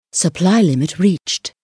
1 channel
supplylimitreached.mp3